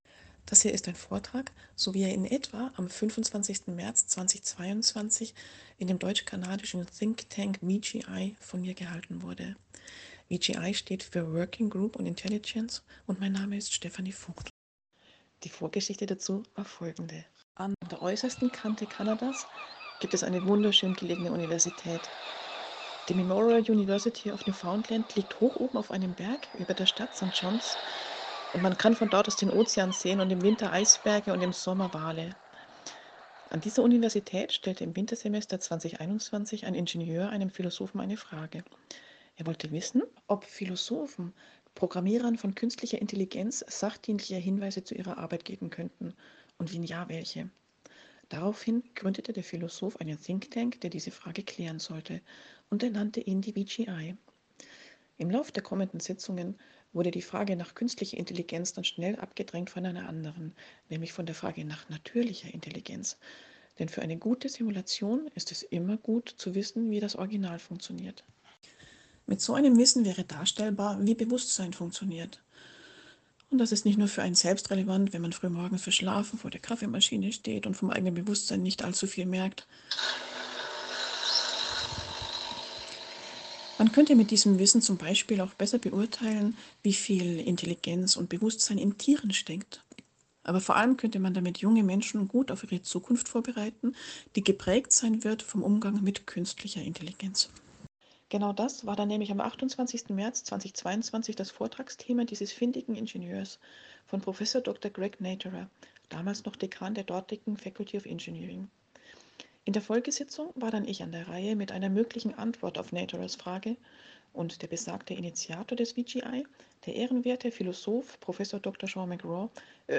Vortrag über Künstliche Intelligenz